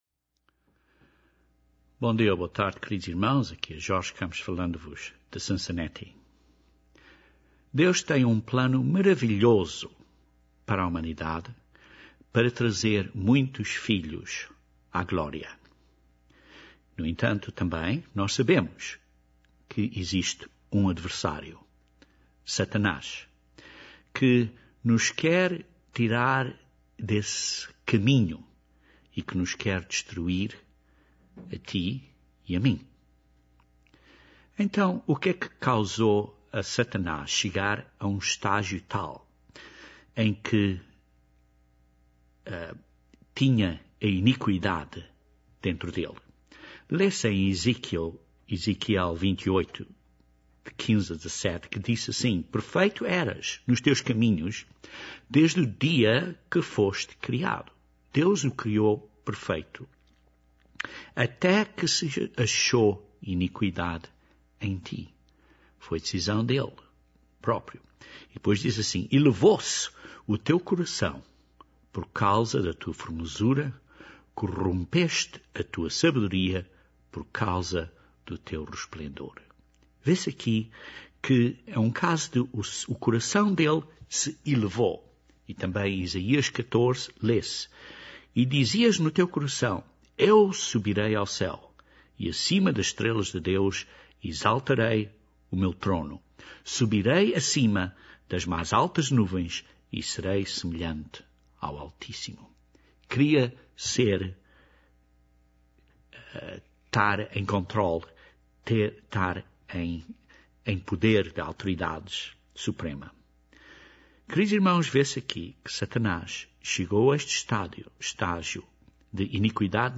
Este sermão descreve 4 atitudes diretamente ligadas à cerimónia do lava-pés. Estas atitudes são diretamente opostas à mentalidade de Satanás, mas são uma linda descrição da mentalidade de Jesus Cristo.